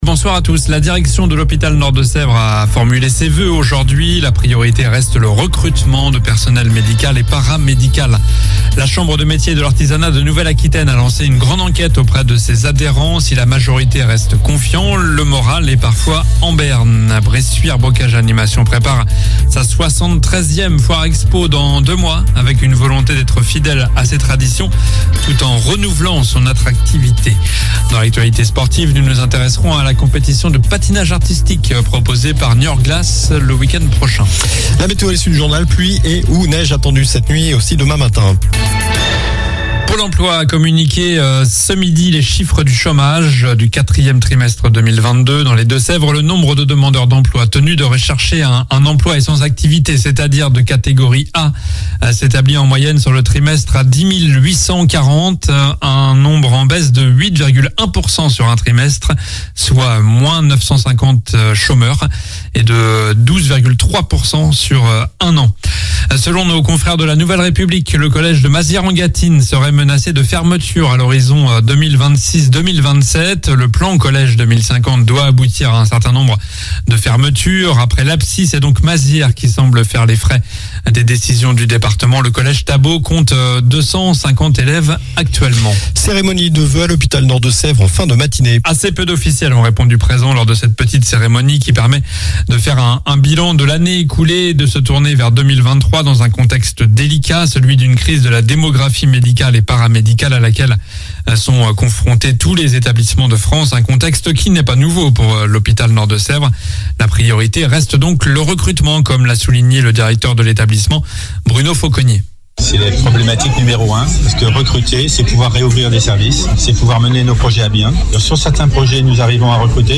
Journal du mercredi 25 janvier (soir)